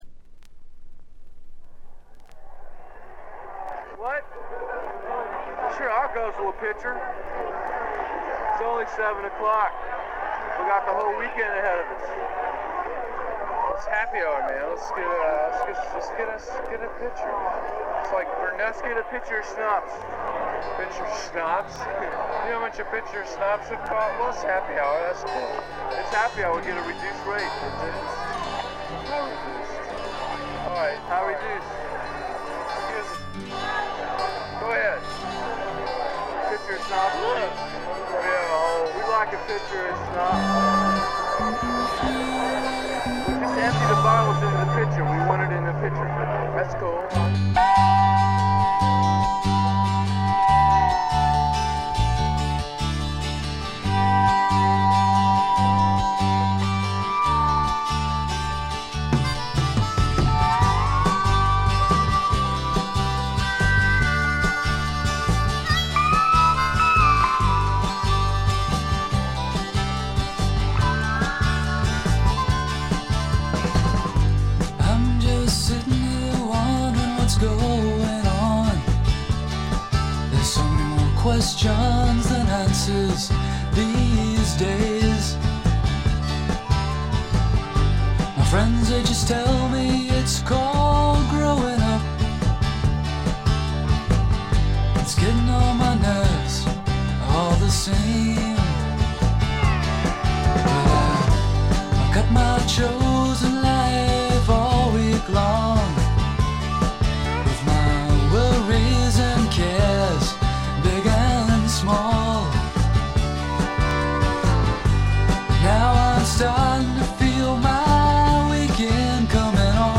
内容はポップでAOR的なサウンドが印象的なシンガー・ソングライター・アルバム。
試聴曲は現品からの取り込み音源です。